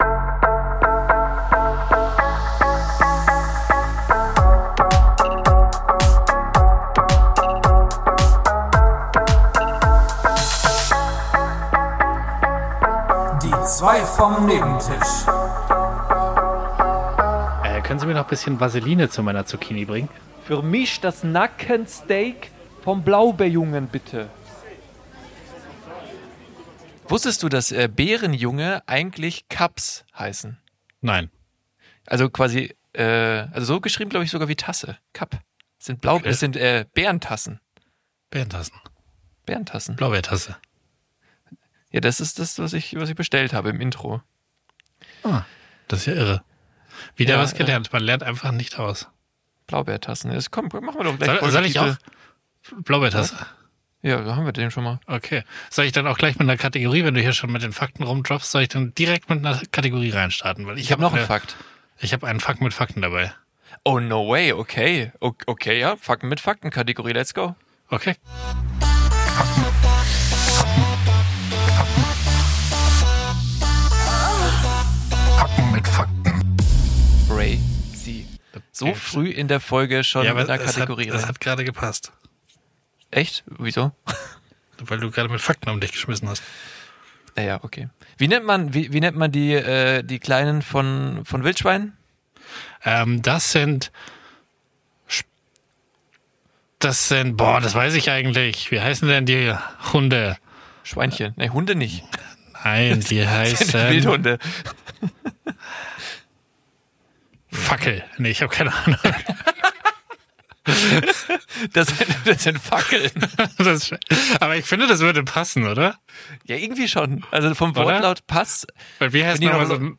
Einfälle, Zweifel und Drei Tiefgründige Fragen beschäftigen die beiden vom Nebentisch intensiv. Es beginnt föhlich und albern und wird zum Ende richtig deep.